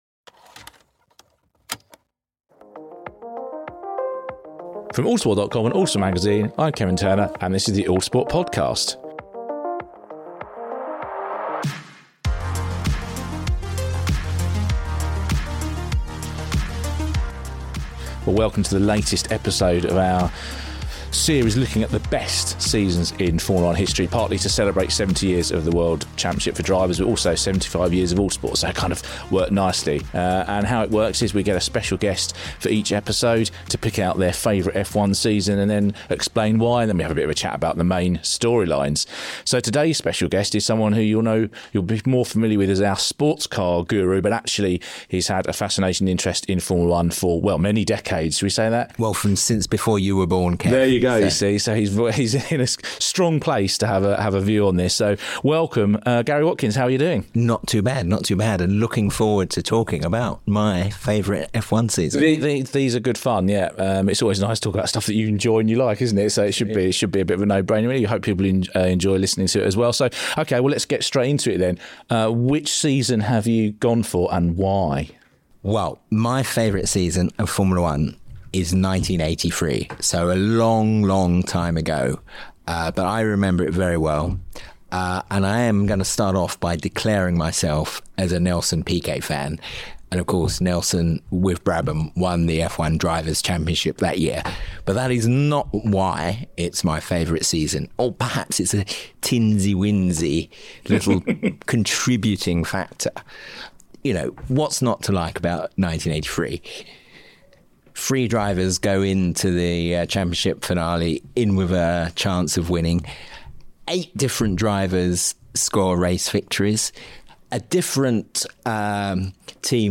sits down with journalists and broadcasters past and present, to talk about their favourite F1 season